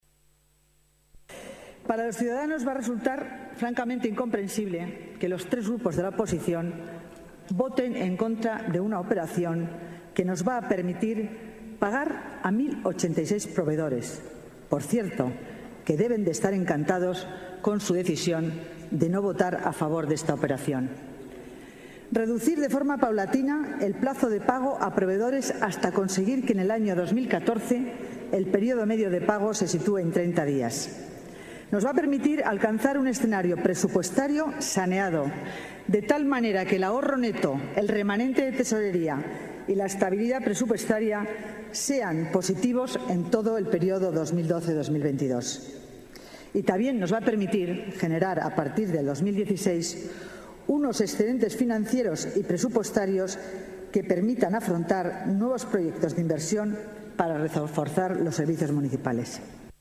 Nueva ventana:Declaraciones delegada Hacienda, Concepción Dancausa: Pleno extraordinario, pago a proveedores es realidad